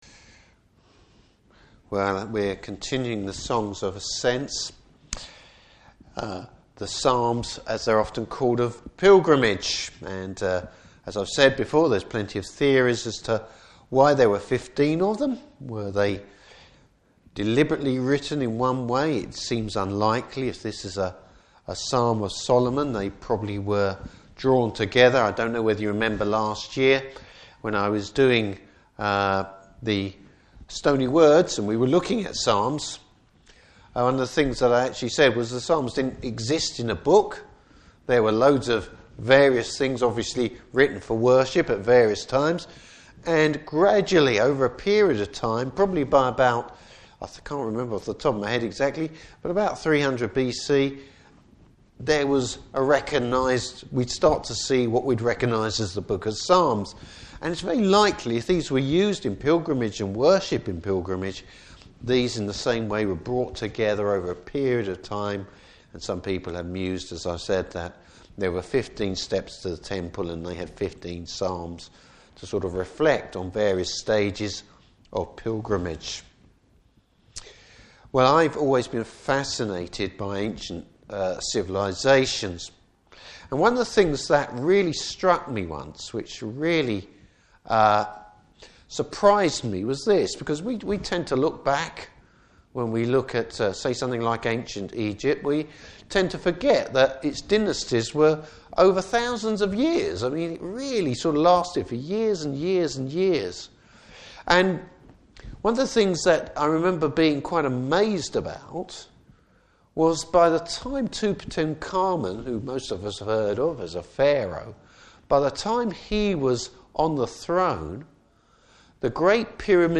Service Type: Evening Service Bible Text: Psalm 127.